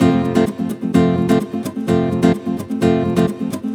VEH2 Nylon Guitar Kit 128BPM
VEH2 Nylon Guitar Kit - 17 F maj.wav